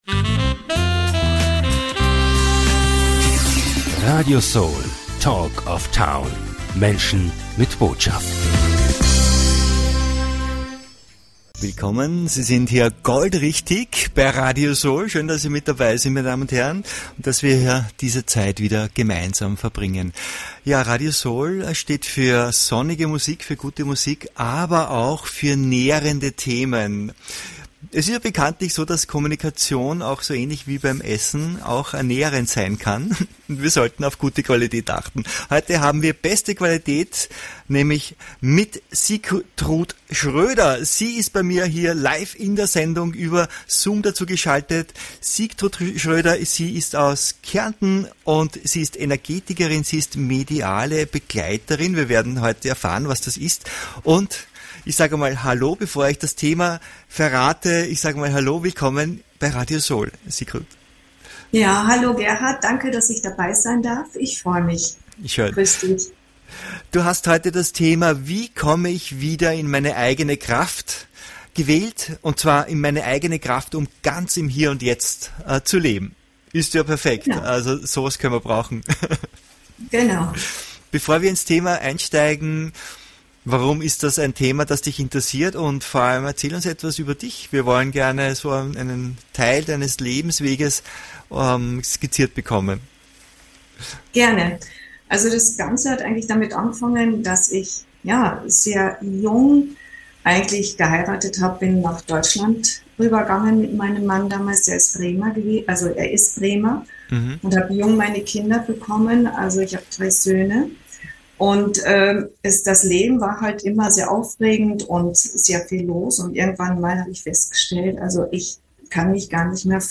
Ein Gespräch voller Aha-Momente, Herzensweisheit und praktischer Impulse für deine persönliche Entwicklung.